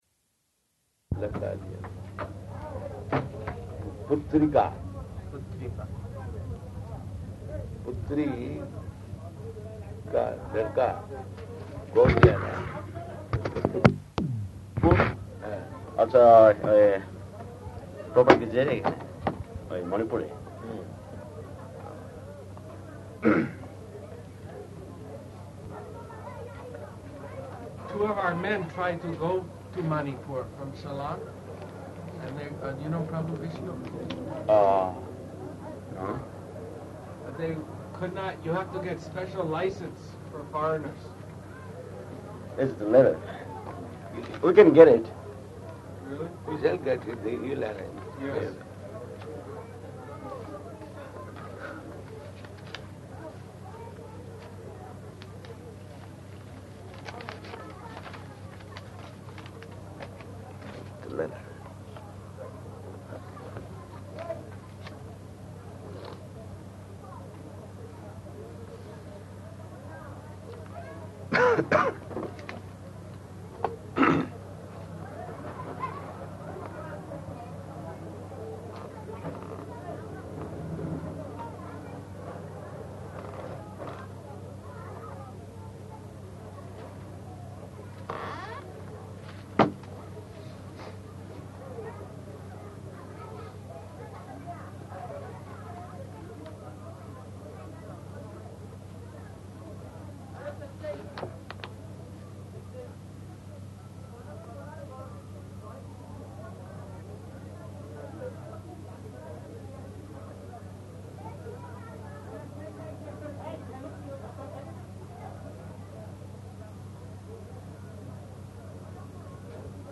-- Type: Conversation Dated: January 30th 1977 Location: Bhubaneswar Audio file